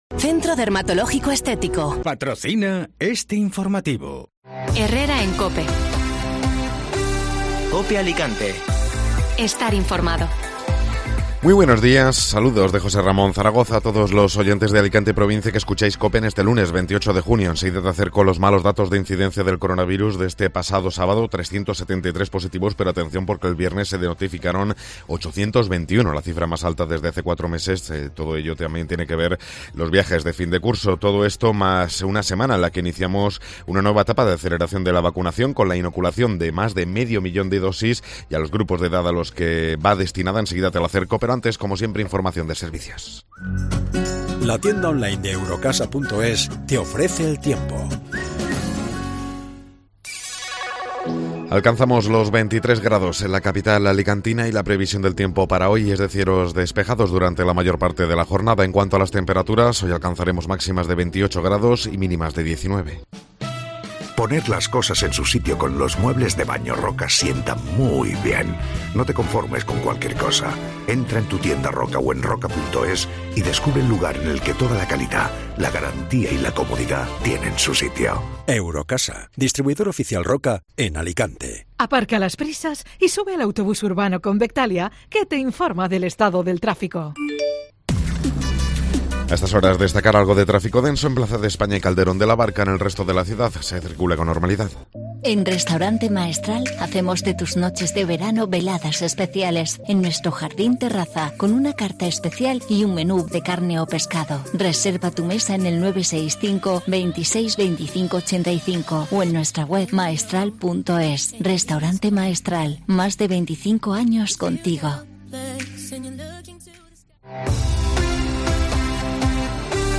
Informativo Matinal (Lunes 28 de Junio)